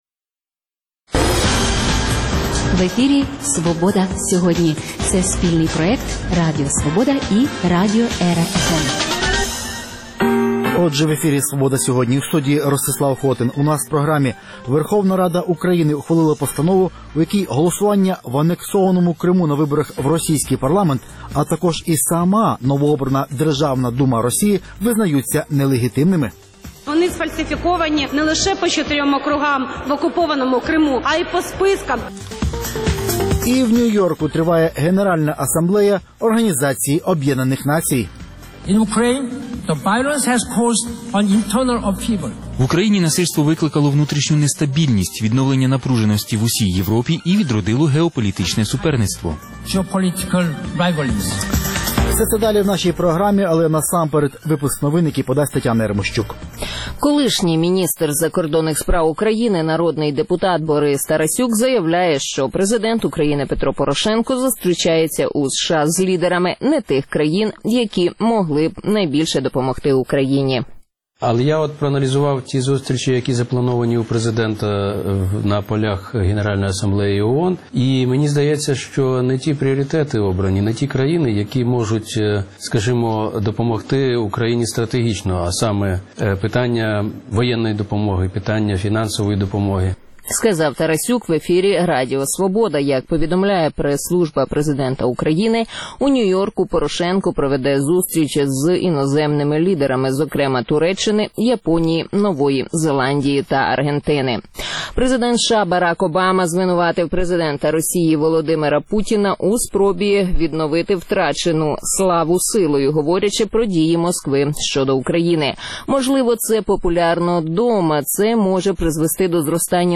Інтерв’ю з колишнім президентом Європарламенту Патом Коксом про українські реформи і євроінтеграцію; Як в Полтавській області вживають захисних засобів проти полімеліту – спеціальний репортаж.